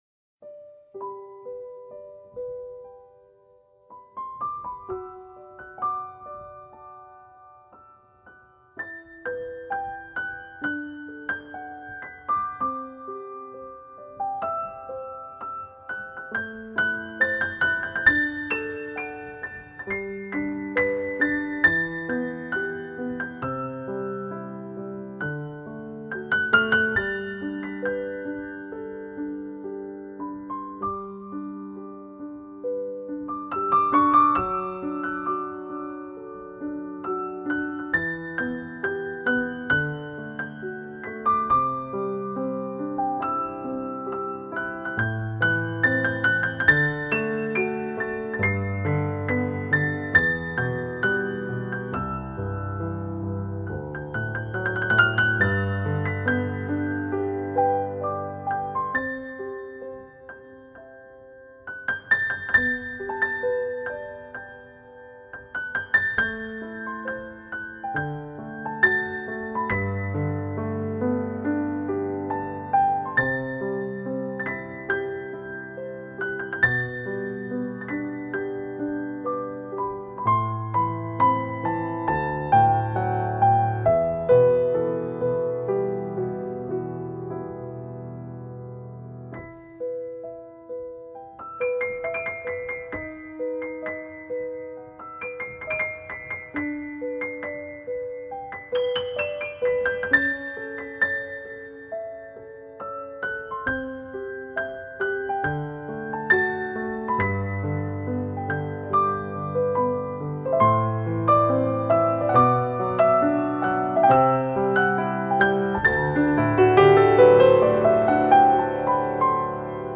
整張專輯就如同它的封面一樣，充滿了春天的氣息。